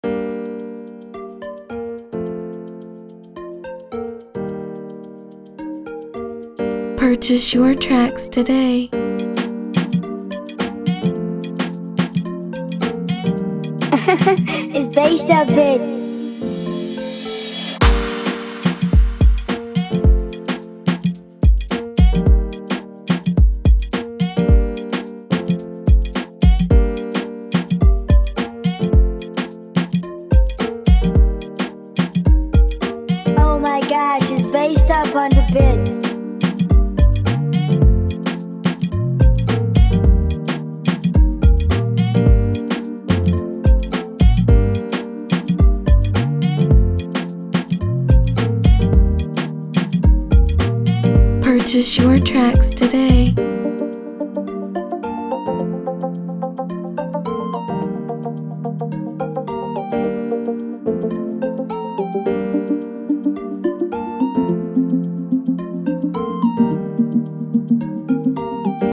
an Afrobeat instrumental
This Nigerian-inspired production
vibrant energy